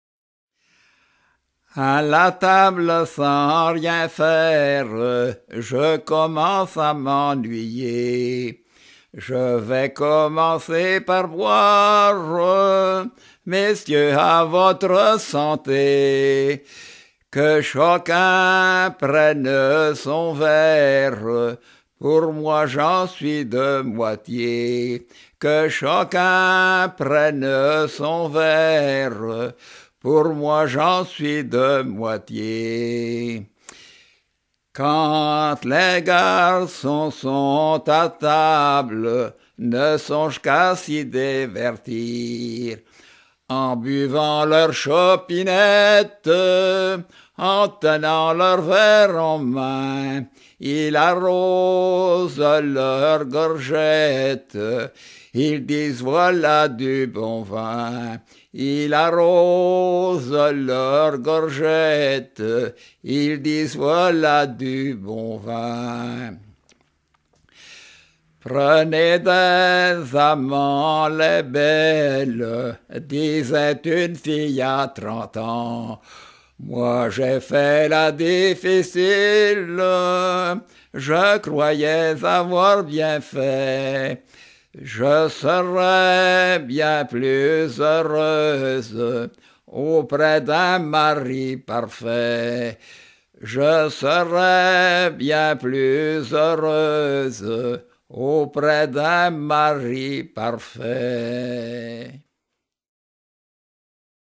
Saint-Malô-du-Bois
circonstance : bachique
Genre strophique